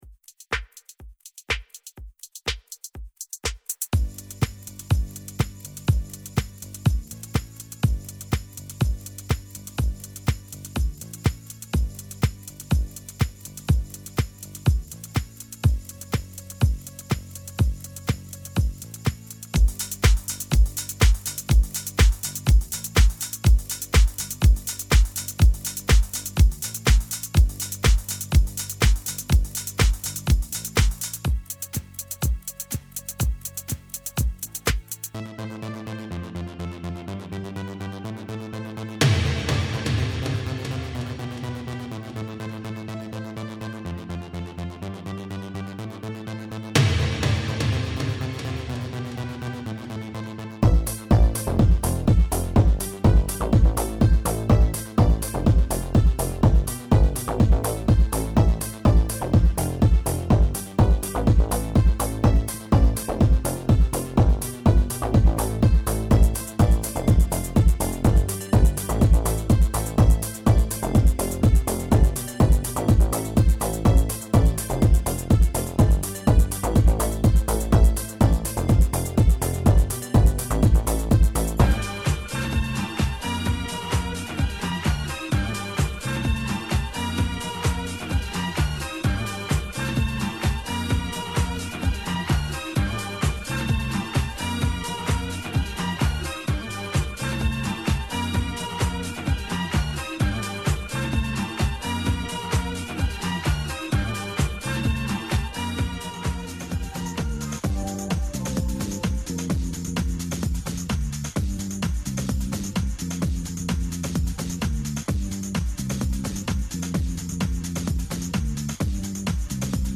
90s Dance Medley Practise Track